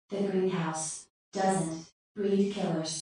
Electronic Voice Phenomena